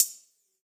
UHH_ElectroHatD_Hit-21.wav